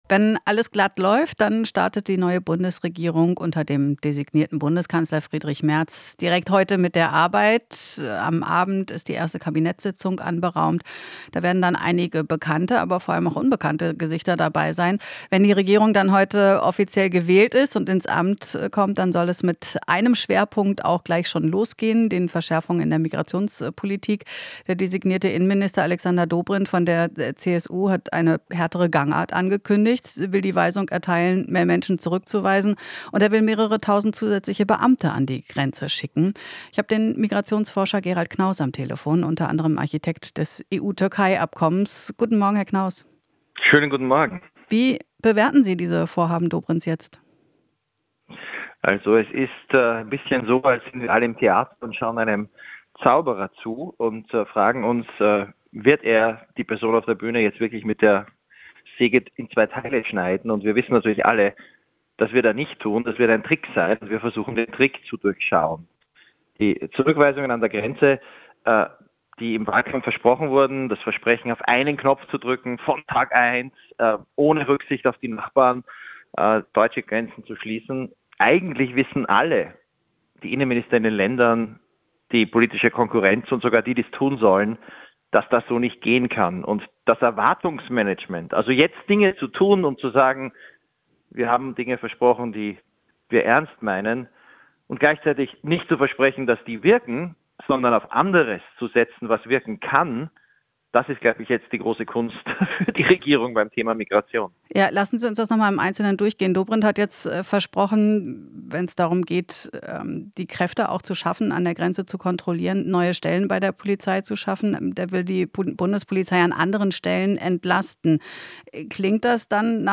Interview - Migrationsforscher: Dobrindts Pläne können nicht funktionieren